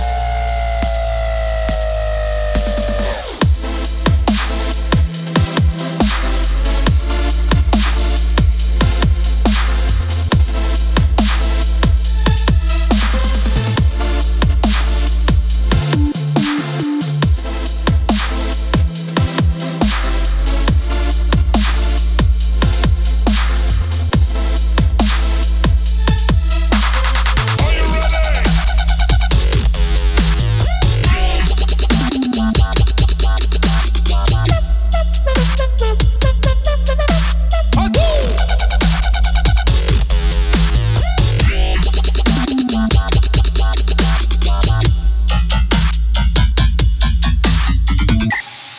זה רק הדרופ עדיין לא בניתי את כל המקצב